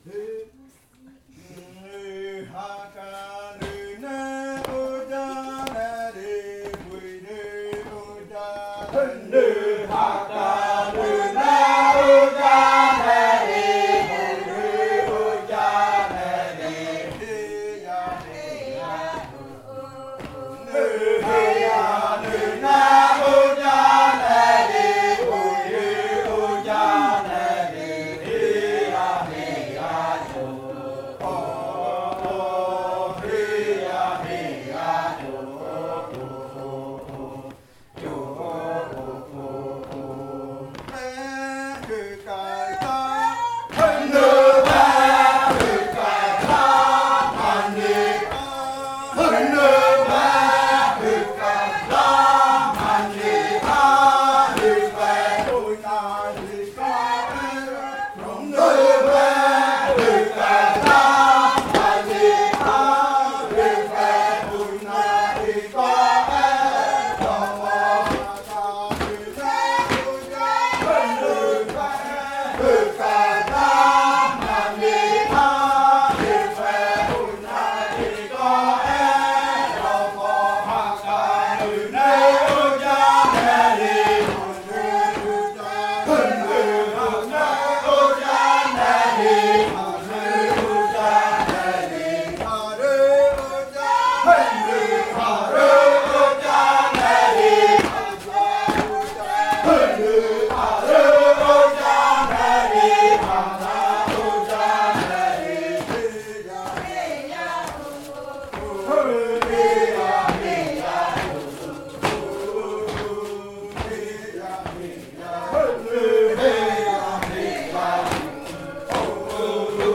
Canto de saltar de la variante jaiokɨ
Leticia, Amazonas
con el grupo de cantores bailando en Nokaido.
with the group of singers dancing in Nokaido. This song is part of the collection of songs from the yuakɨ murui-muina ritual (fruit ritual) of the Murui people, a collection that was compiled by the Kaɨ Komuiya Uai Dance Group with support from UNAL, Amazonia campus.